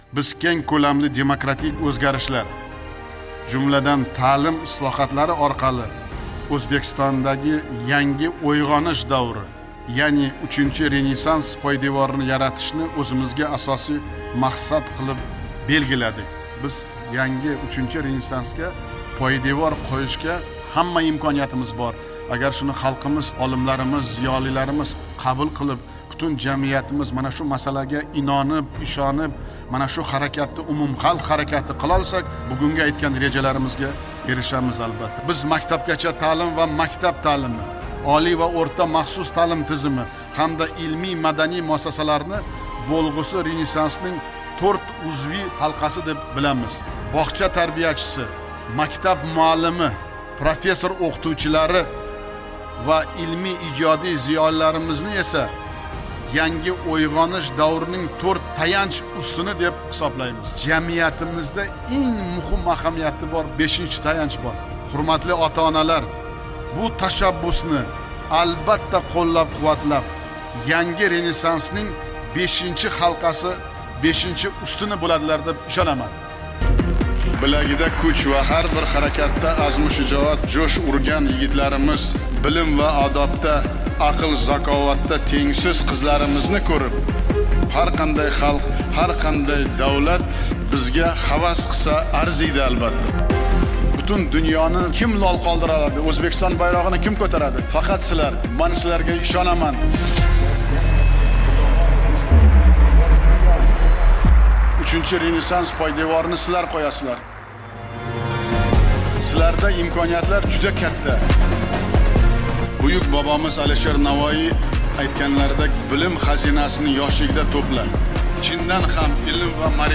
Uchinchi renesans prezident nutqidan.mp3